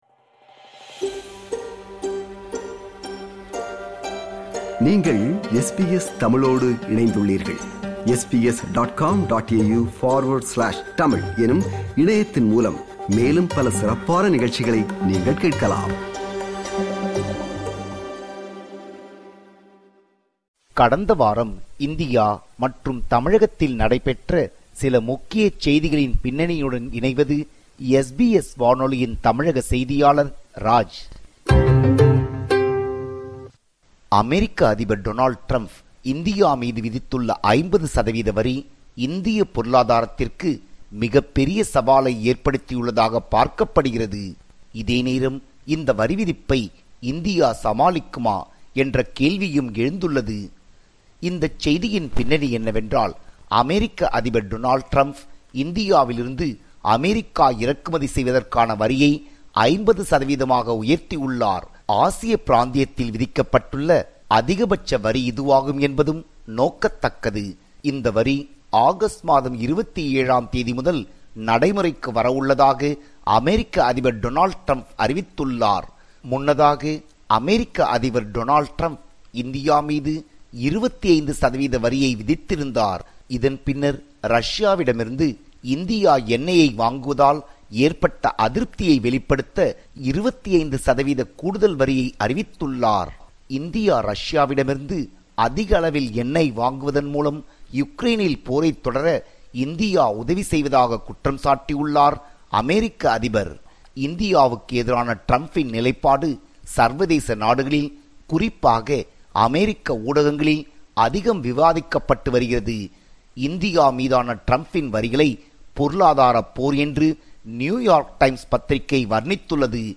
இவை உள்ளிட்ட செய்திகளோடு “செய்திகளின் பின்னணி” நிகழ்ச்சிக்காக இணைகிறார் நமது தமிழக செய்தியாளர்